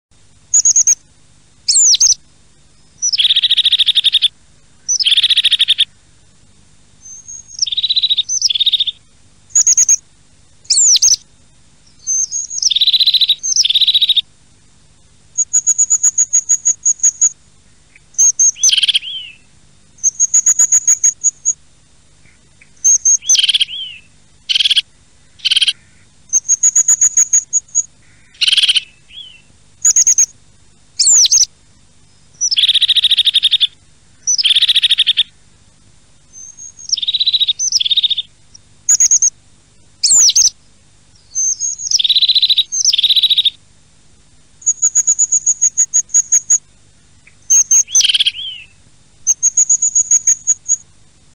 Голос гренадерки для привлечения птиц к кормушке (ставьте недалеко от кормушки, можно передавать звук по Bluetooth-колонке)